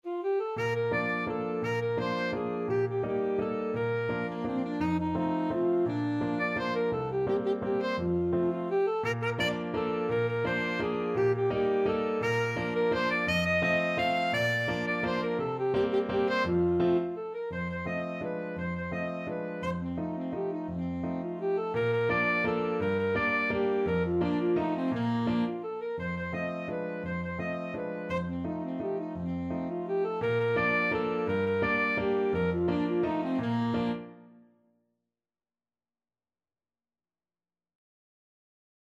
Classical Schubert, Franz Cotillon D.976 Alto Saxophone version
Alto Saxophone
Bb major (Sounding Pitch) G major (Alto Saxophone in Eb) (View more Bb major Music for Saxophone )
3/4 (View more 3/4 Music)
Bb4-F6
Classical (View more Classical Saxophone Music)